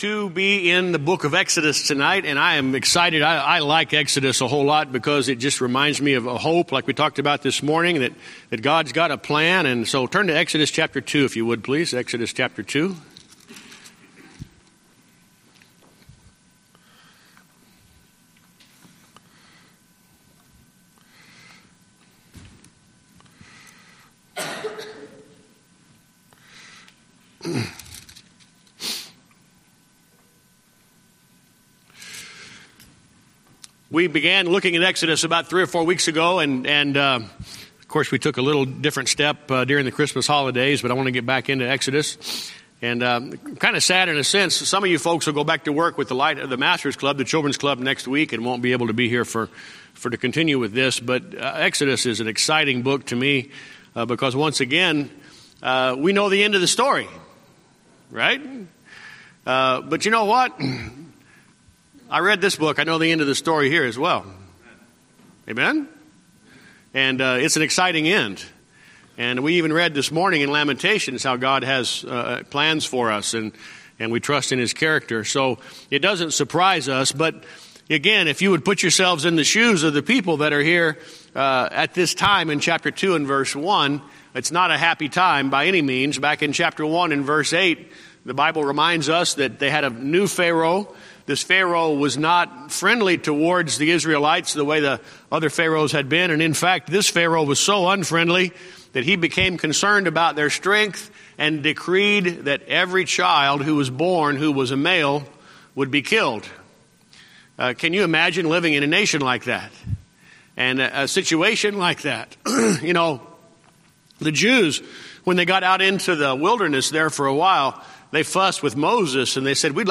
Sermons
Series: Guest Speaker